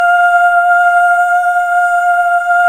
Index of /90_sSampleCDs/Club-50 - Foundations Roland/VOX_xFemale Ooz/VOX_xFm Ooz 1 S